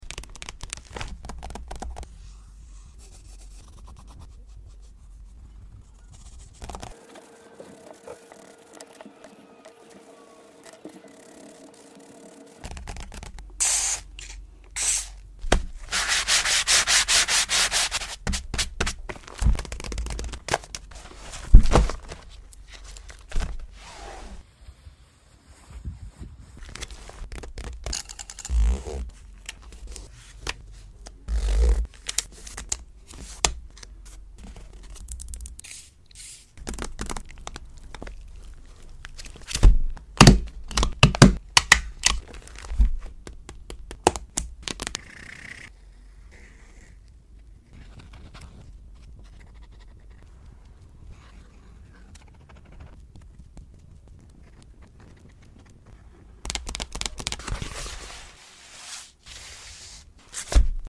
ASMR wreck this journal! It’s sound effects free download